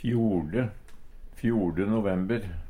fjorde - Numedalsmål (en-US)